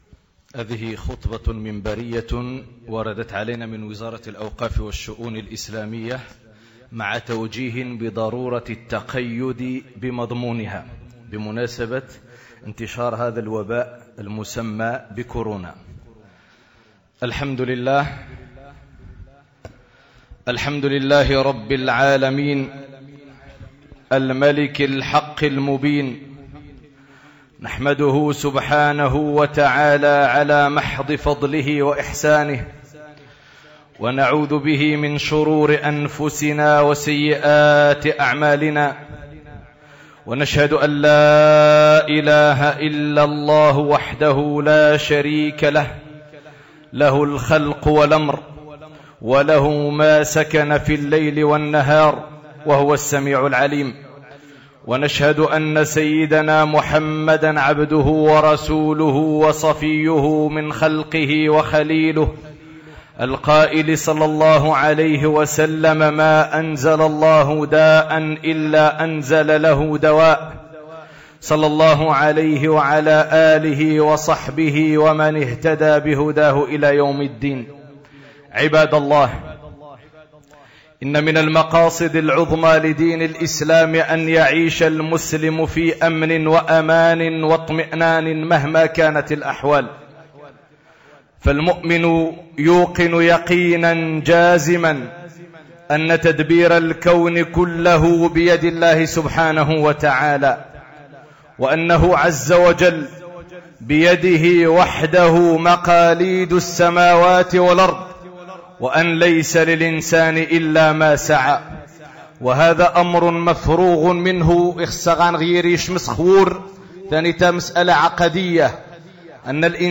خطبة منبربة موحدة حول انتشار فيروس كورونا المستجد